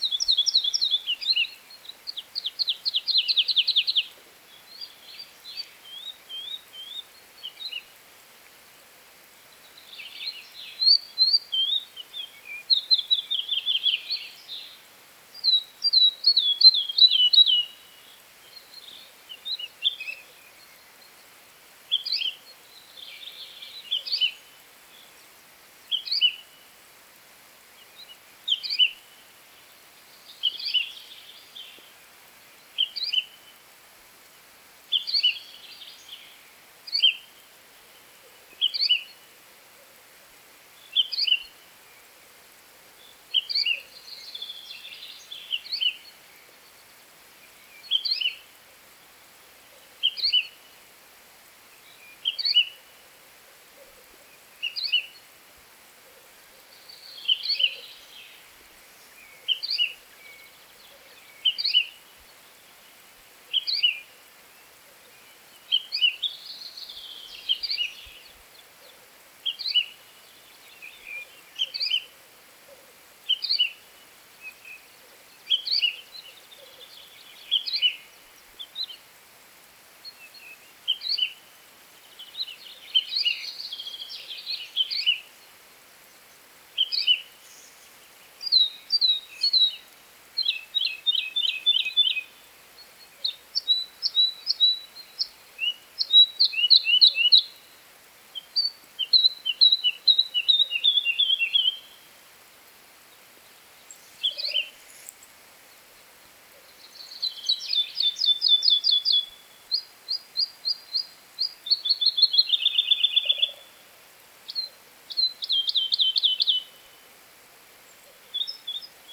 Птицы -> Жаворонковые ->
лесной жаворонок, Lullula arborea
СтатусПара в подходящем для гнездования биотопе